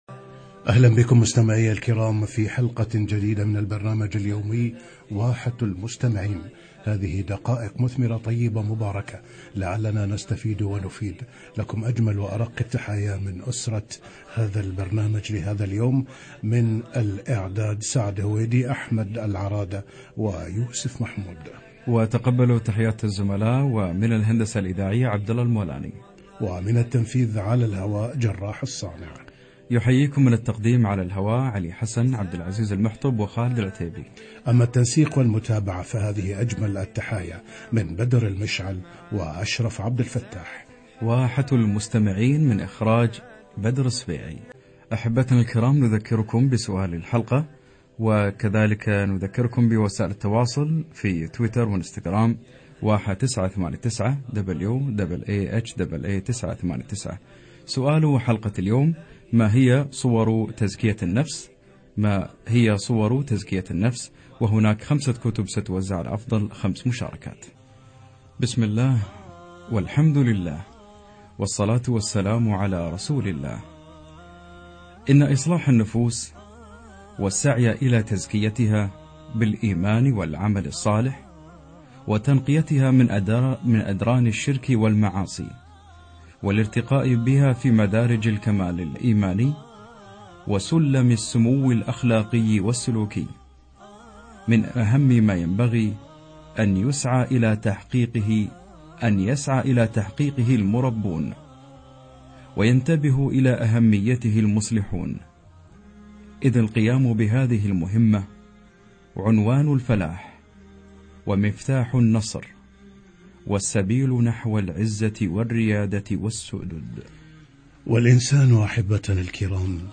لقاء في برنامج واحة المستمعين - تزكية النفس